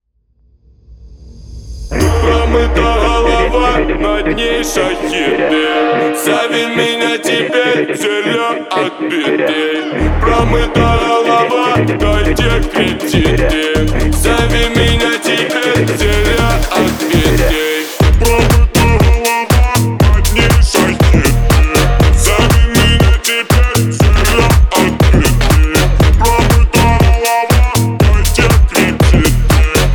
Electronic